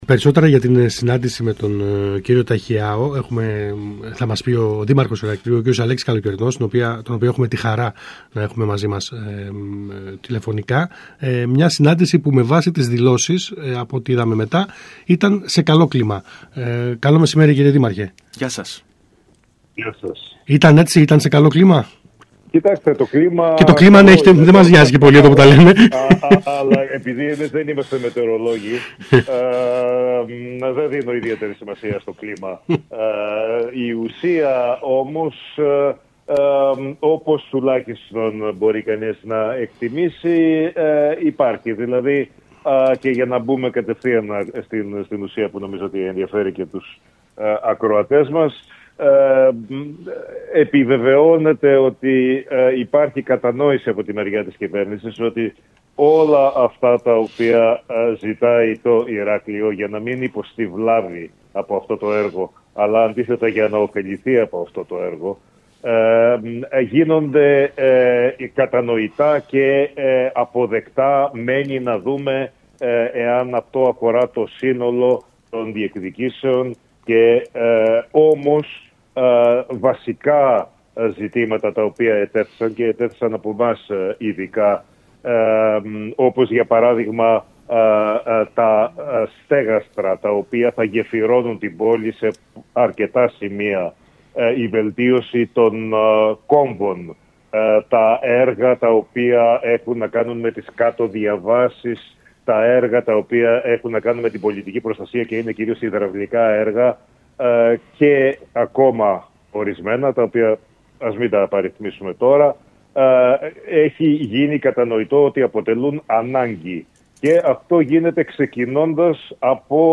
Ακούστε εδώ όσα είπε ο Δήμαρχος Ηρακλείου Αλέξης Καλοκαιρινός στον ΣΚΑΙ Κρήτης 92.1: